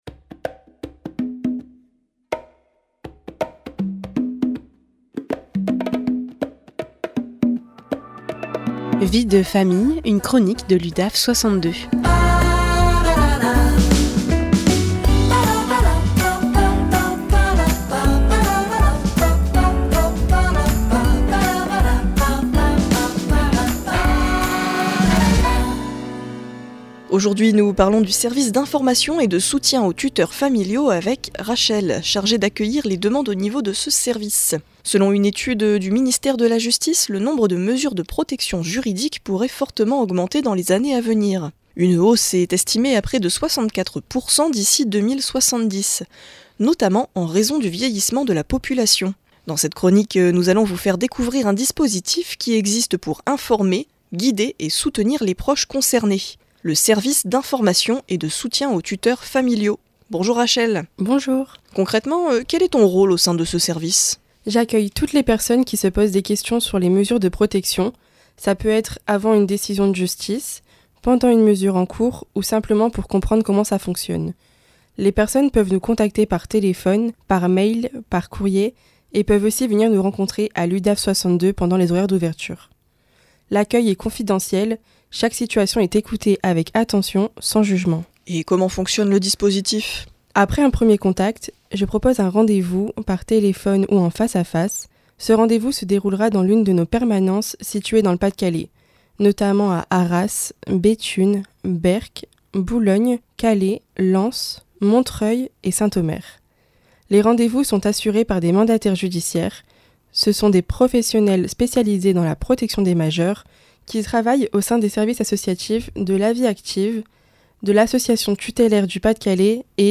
Les professionnels de l’Udaf 62 interviennent au micro de PFM Radio à Arras, en proposant des chroniques sur divers sujets en lien avec leurs services respectifs.
Vie de Famille, une chronique de l’Udaf62 en live sur RADIO PFM 99.9